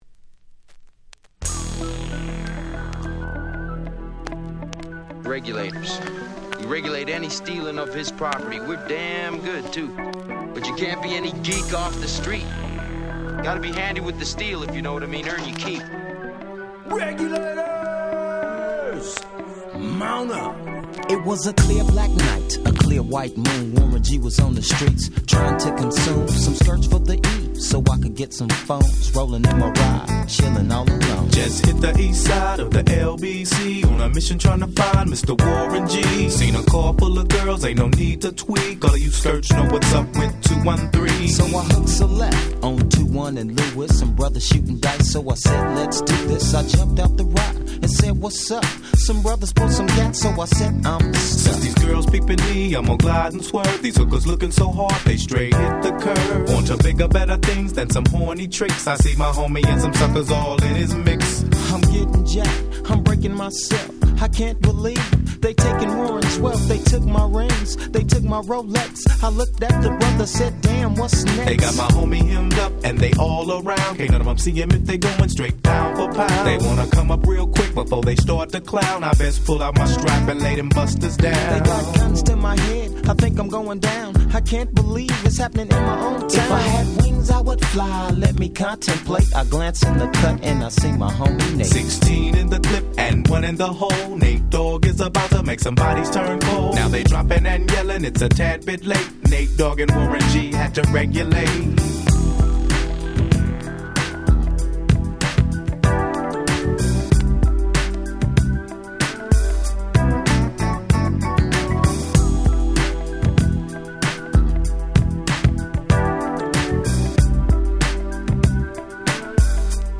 94' Smash Hit Hip Hop / G-Rap / Gangsta Rap !!